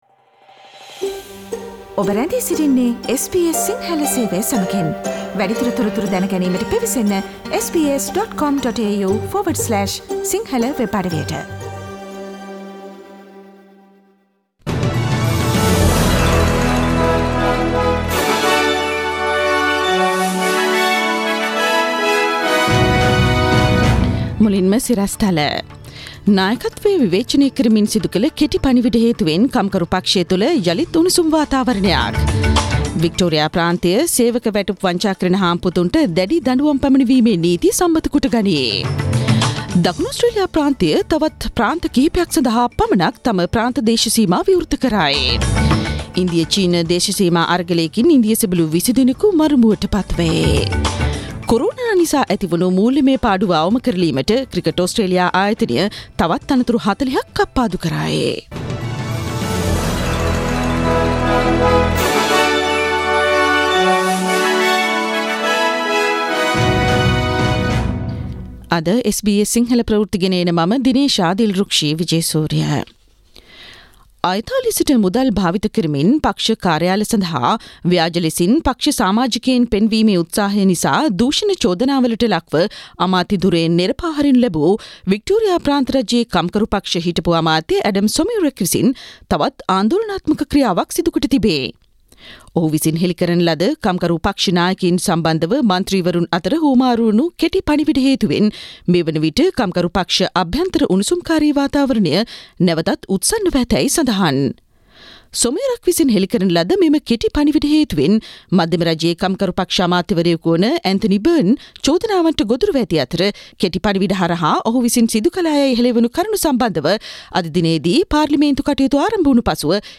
Daily News bulletin of SBS Sinhala Service: Thursday 18 June 2020
Today’s news bulletin of SBS Sinhala radio – Thursday 18 June 2020.